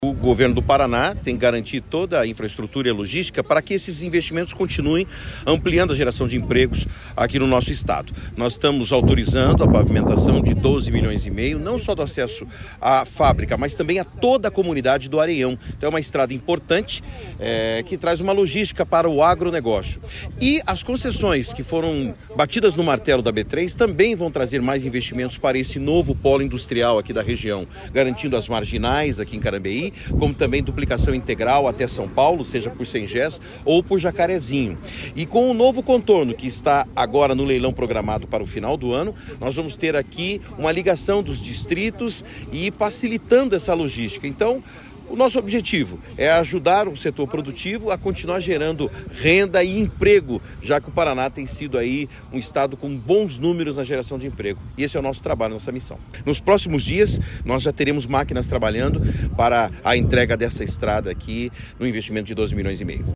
Sonora do secretário Estadual da Infraestrutura e Logística, Sandro Alex, sobre o lançamento da pedra fundamental da nova fábrica da Ambev em Carambeí, nos Campos Gerais